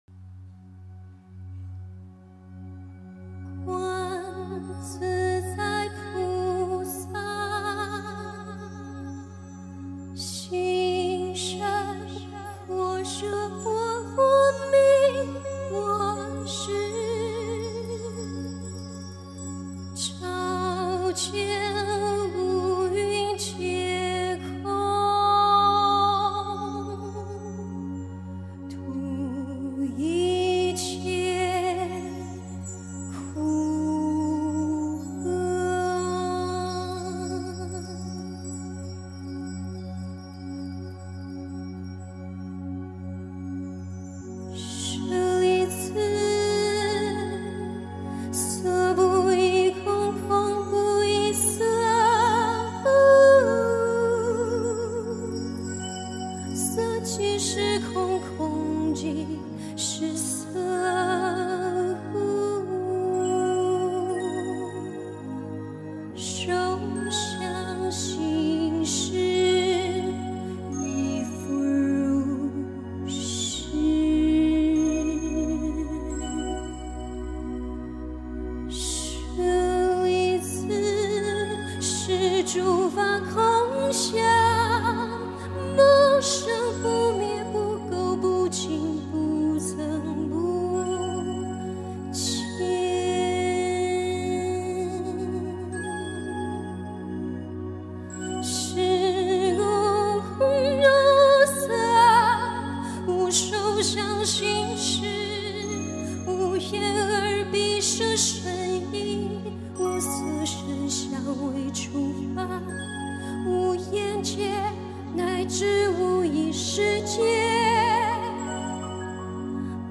音频：《心经演唱》新加坡一叶一菩提千人素食分享会！